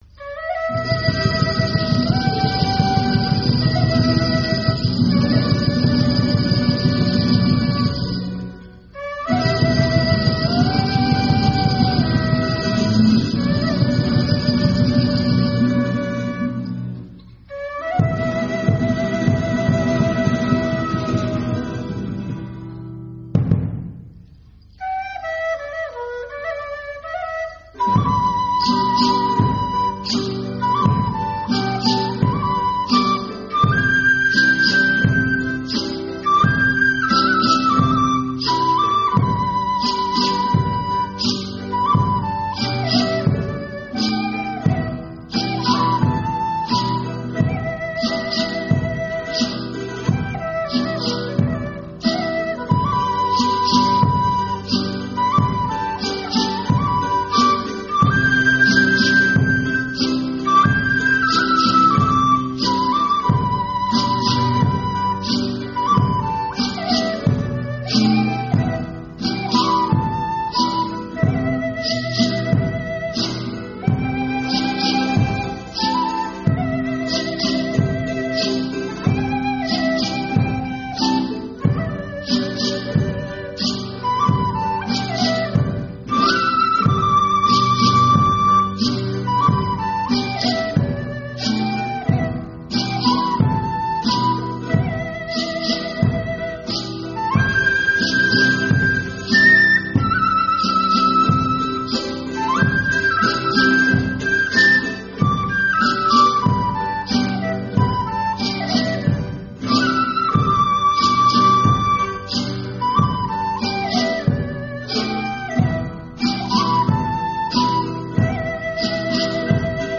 オカリナ曲名 ケーナ・サンポーニャ曲名